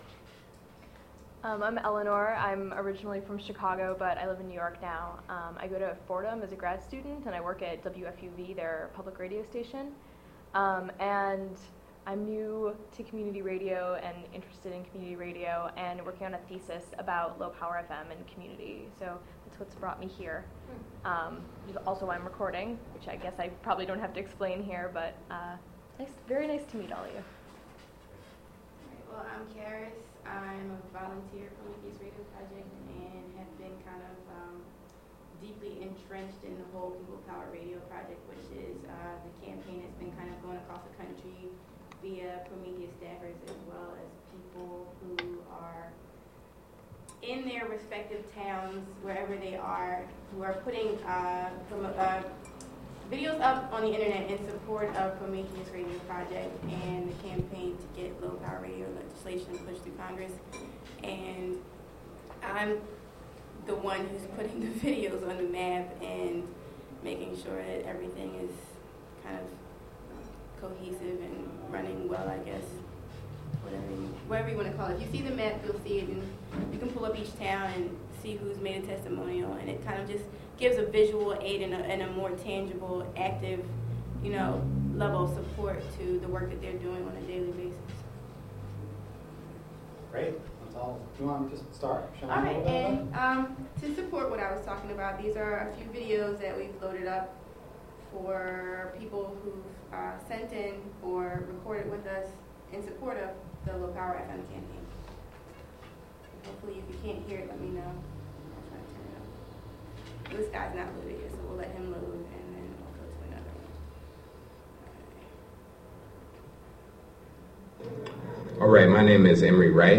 WGXC/Prometheus Radio Project Station Barnraising: Sep 24, 2010 - Sep 26, 2010